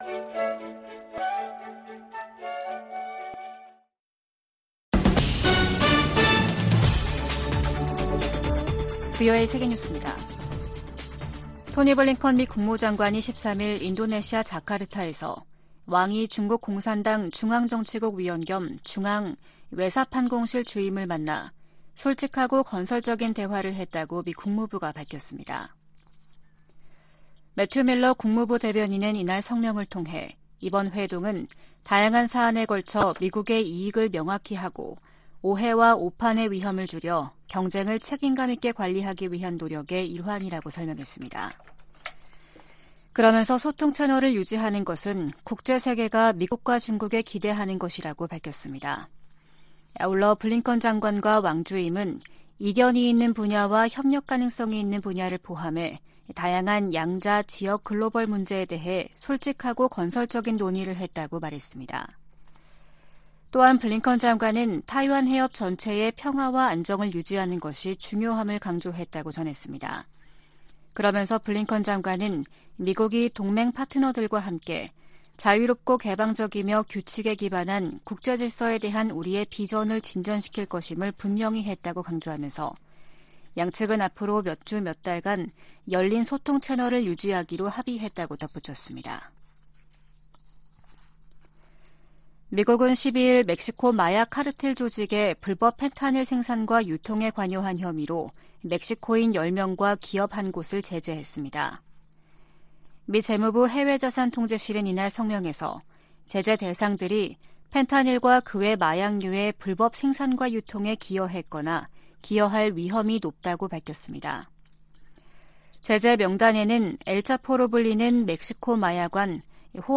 VOA 한국어 아침 뉴스 프로그램 '워싱턴 뉴스 광장' 2023년 7월 14일 방송입니다. 13일 북한 당국이 전날(12일) 발사한 탄도미사일이 고체연료 대륙간탄도미사일(ICBM) 화성-18호라고 밝혔습니다. 미국 정부와 의회, 유엔과 나토 사무총장, 한일 정상, 미한일 합참의장이 북한 정권의 장거리탄도미사일 발사를 강력히 규탄하며 대화에 나설 것을 촉구했습니다. 북한 주민들에게 자유와 진실의 목소리를 전해야 한다고 미국 의원들이 강조했습니다.